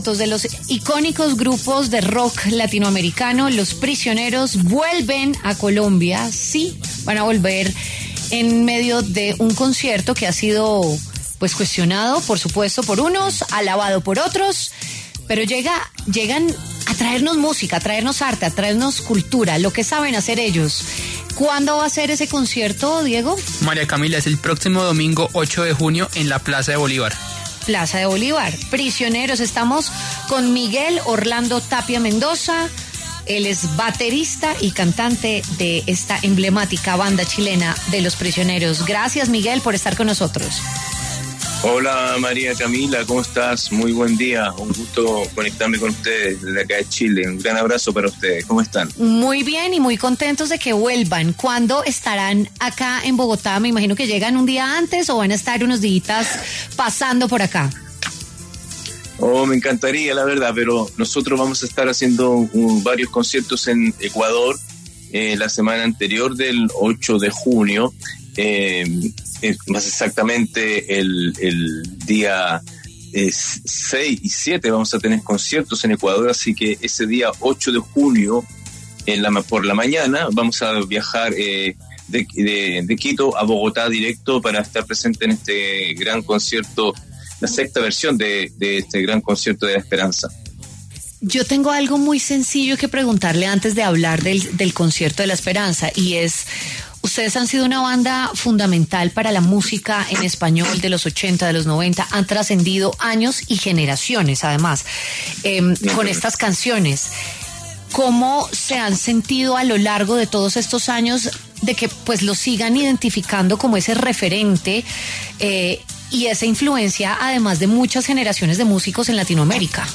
El baterista y cantante Miguel Tapia habló con W Fin de Semana a propósito del regreso de la banda a Bogotá.